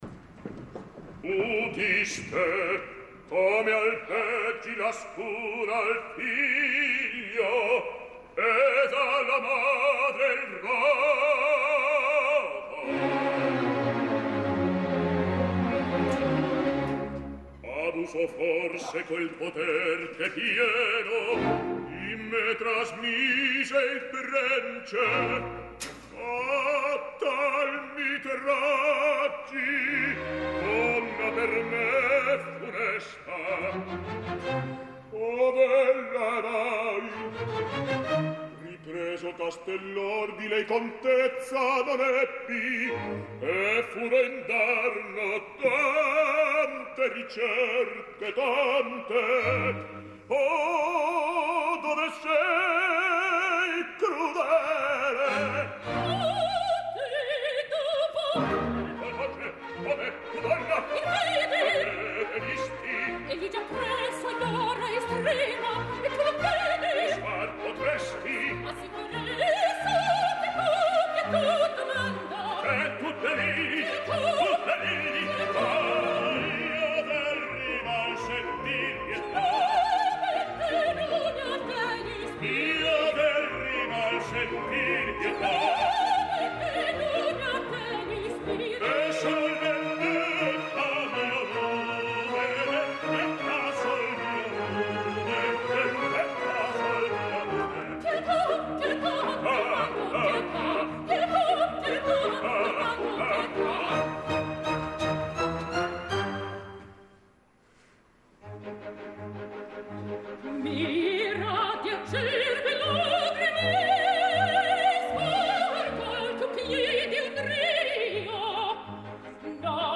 I el duo amb Leonora de l’acte quart “Udite? Come albeggi …Mira, di acerbe lagrime… Vivrà!… contende il giubilo”
Leonora: Angela Meade
The Metropolitan Opera Orchestra and Chorus
Director musical:Marco Armiliato
Metropolitan Opera House,New York 13 de febrer de 2016
Radio Clásica va retransmetre dissabte passat la darrera representació en directe des del MET.
03-duo-luna-leonora.mp3